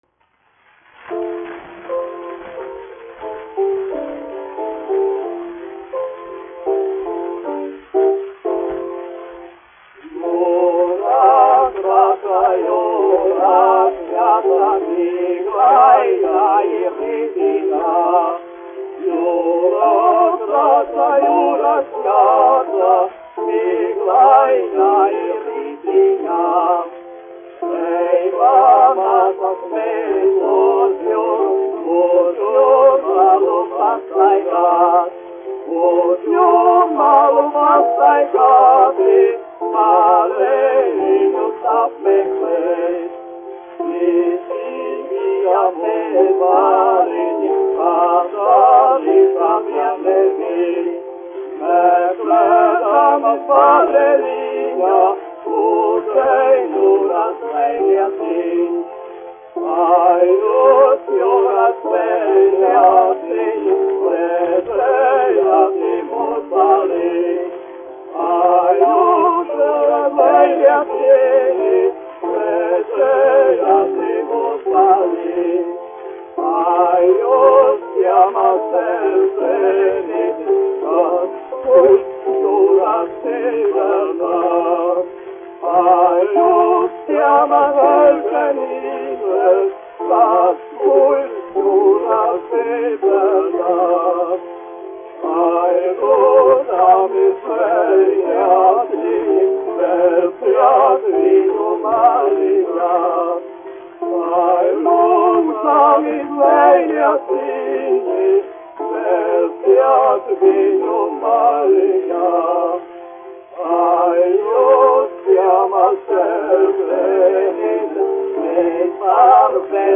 1 skpl. : analogs, 78 apgr/min, mono ; 25 cm
Latviešu tautasdziesmas
Vokālie dueti ar klavierēm
Rīgas Jaunā Latviešu teātra dziedātāji